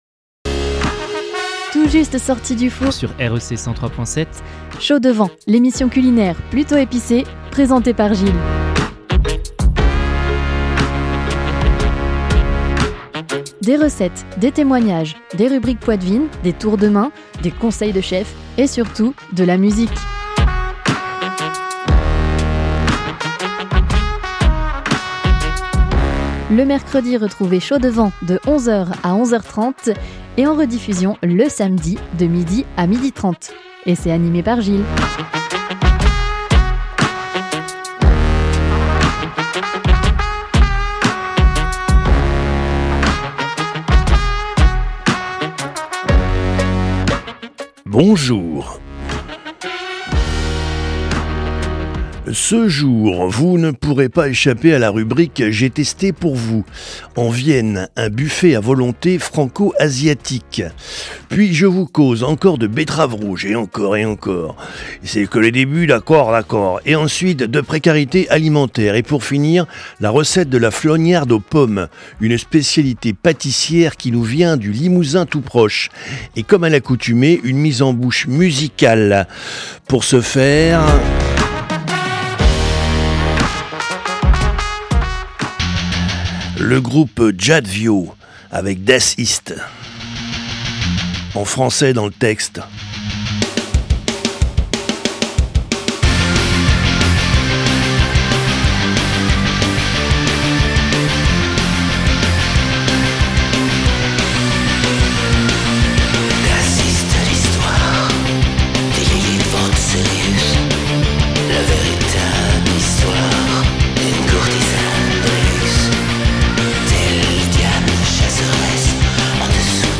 avec anecdotes, témoignages , rubriques , recettes , conseils de chef et musiques !